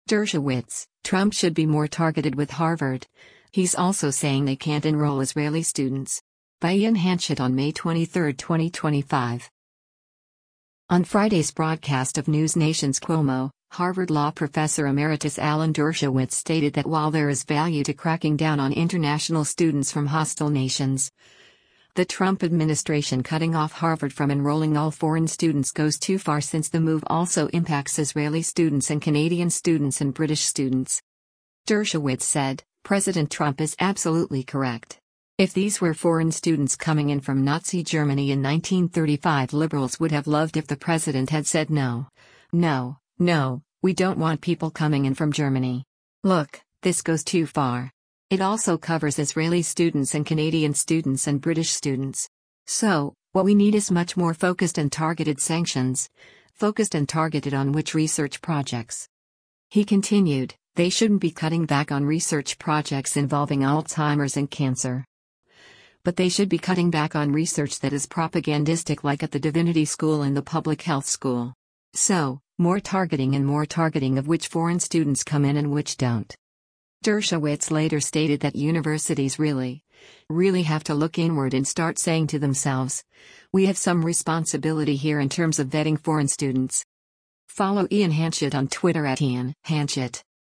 On Friday’s broadcast of NewsNation’s “Cuomo,” Harvard Law Professor Emeritus Alan Dershowitz stated that while there is value to cracking down on international students from hostile nations, the Trump administration cutting off Harvard from enrolling all foreign students goes too far since the move also impacts “Israeli students and Canadian students and British students.”